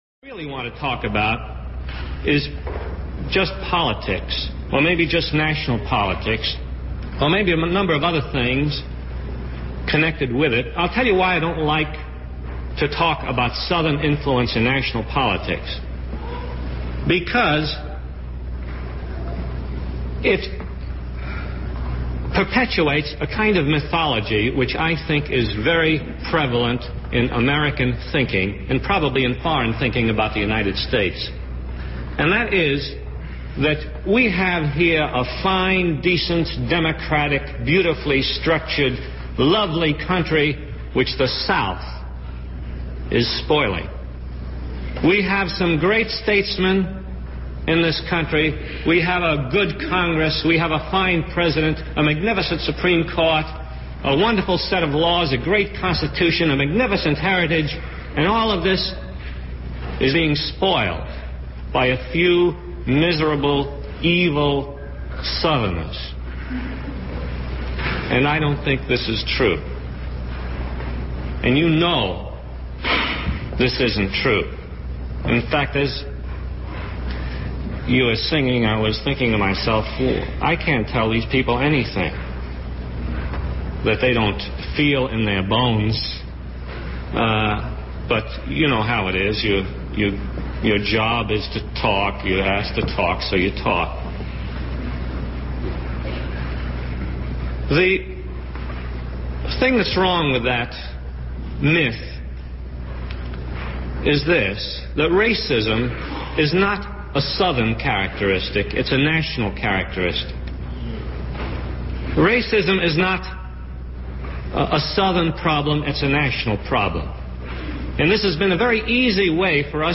KPFA’s podcast, which I subscribe to, recently had some lengthy extracts from a speech by Zinn entitled: “The Southern Influence on National Politics” [kpfa] As always, Zinn is devastating:
I’ve clipped the bit above into an audio file, if you want to hear the original. I don’t think I should post the whole audio (and KPFA don’t provide a complete version, anyway) Zinn’s delivery is interesting: tired-sounding, sharp, and he drawls “southern” like a proper kid that grew up in Brooklyn.